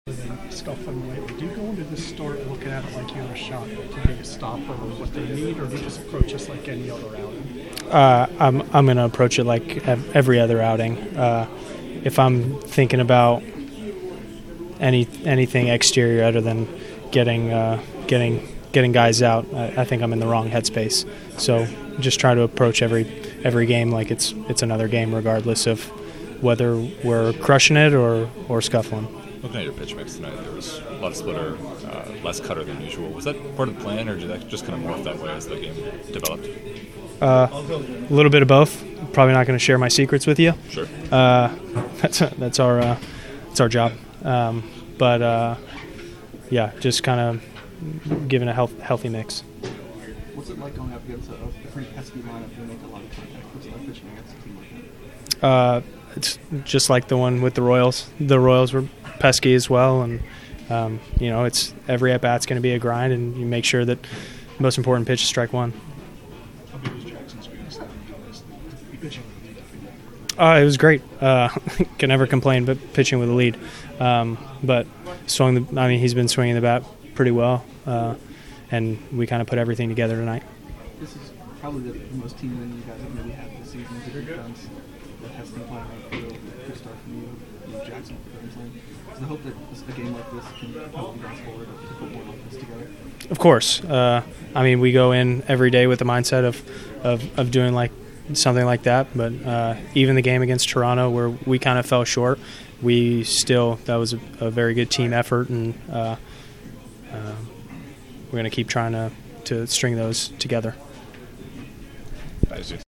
Locker Room Sound